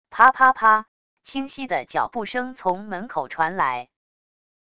啪啪啪……清晰的脚步声从门口传来wav音频